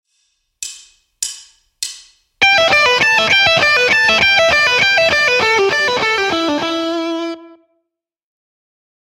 Guitar
Lick 49 - E